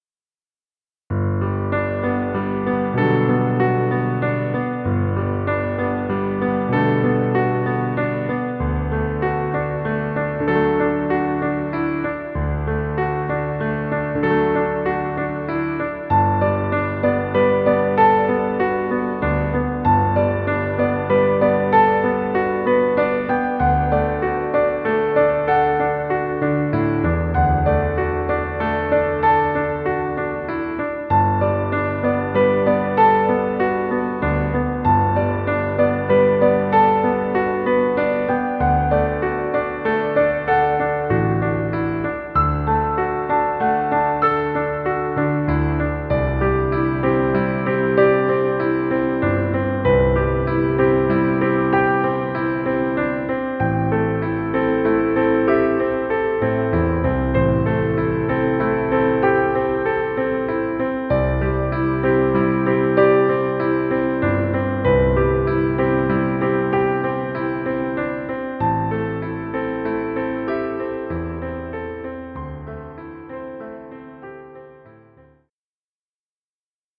Piano music.